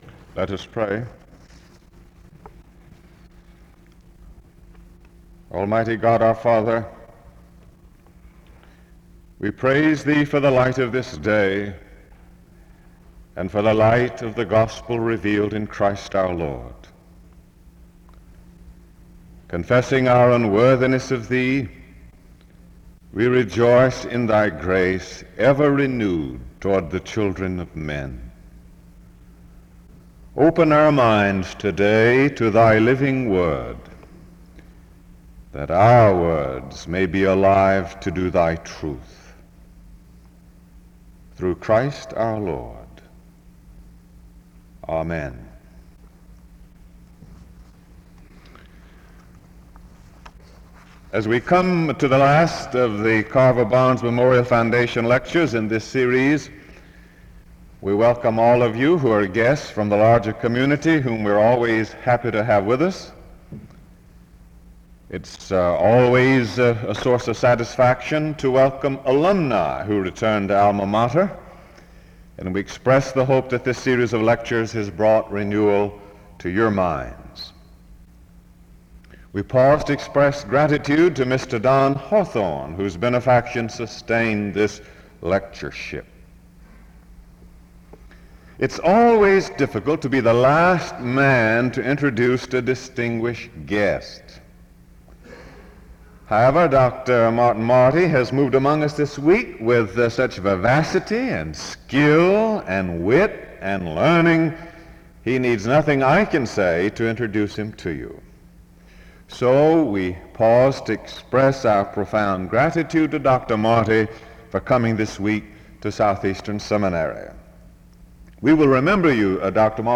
File Set | SEBTS_Carver-Barnes_Lecture_Martin_E_Marty_1968-03-29.wav | ID: 6fcde39d-23de-4e02-b674-0ec8964c7ca0 | Archives & Special Collections at Southeastern